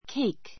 kéik ケ イ ク